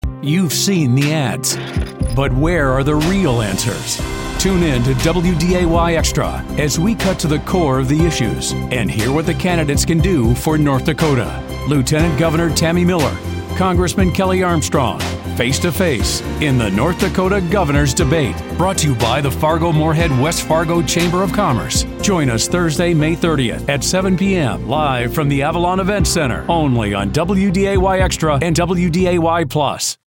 new york : voiceover : commercial : men